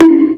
soft-hitnormal.mp3